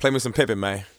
Southside Vox (3).wav